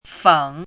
fěng
feng3.mp3